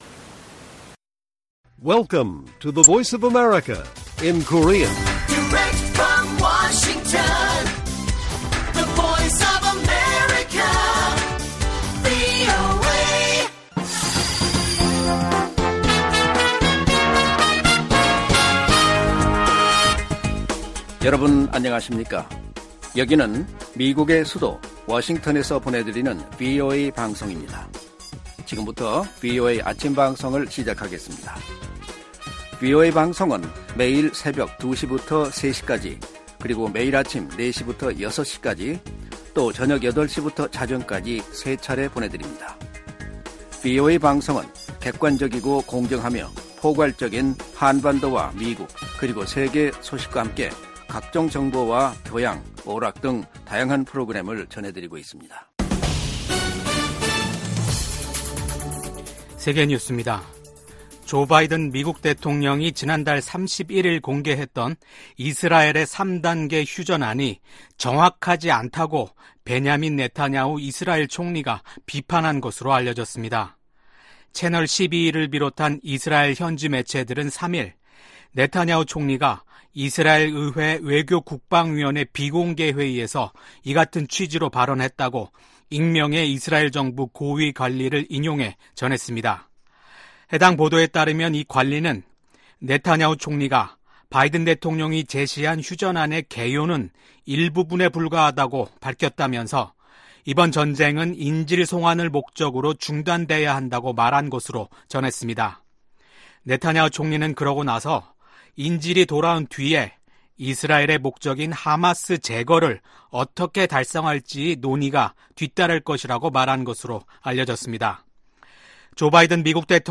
세계 뉴스와 함께 미국의 모든 것을 소개하는 '생방송 여기는 워싱턴입니다', 2024년 6월 4일 아침 방송입니다. '지구촌 오늘'에서는 멕시코 헌정 역사 200년 만에 첫 여성 대통령이 탄생한 소식 전해드리고, '아메리카 나우'에서는 성추문 입막음 의혹 재판에서 유죄평결을 받은 도널드 트럼프 전 대통령은 자신이 수감되면 국민들이 받아들이지 못할 것이라고 밝힌 이야기 살펴보겠습니다.